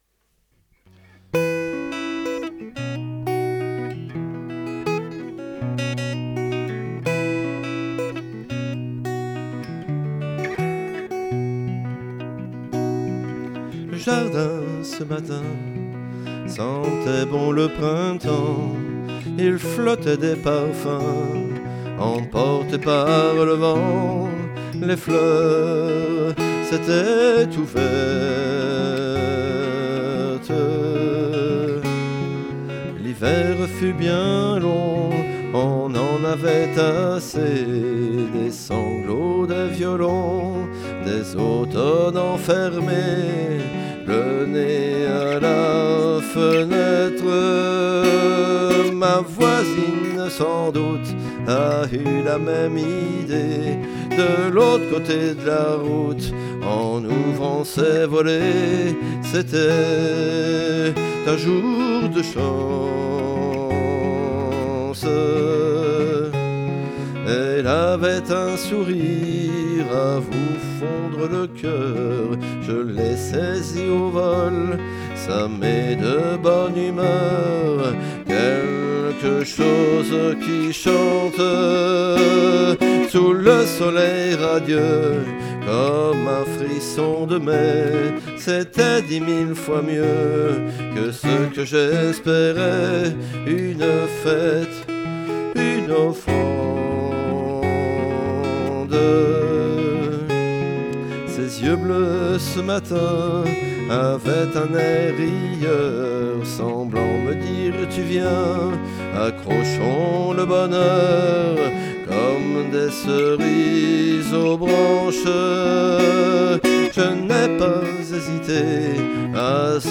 Musique, chant, guitare